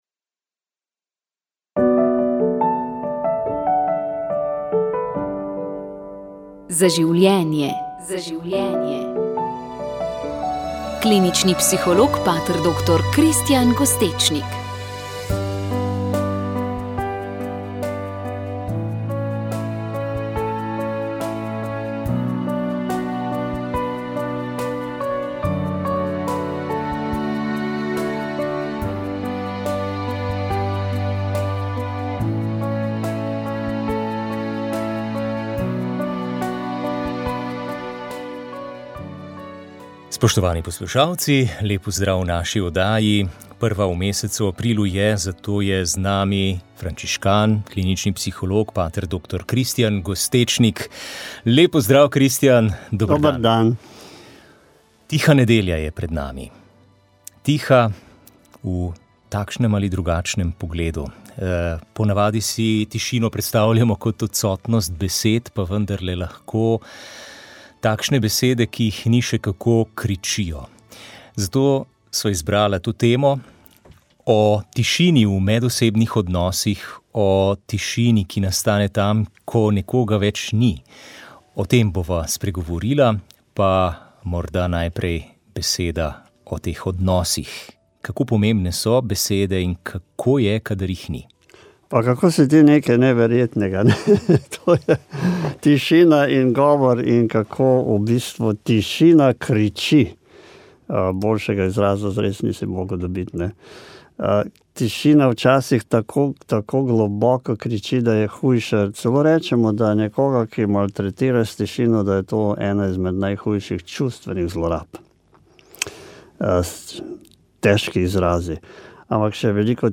Sveta maša
Sv. maša iz župnijske cerkve Svete Elizabete Ogrske Ljubno ob Savinji 26. 5.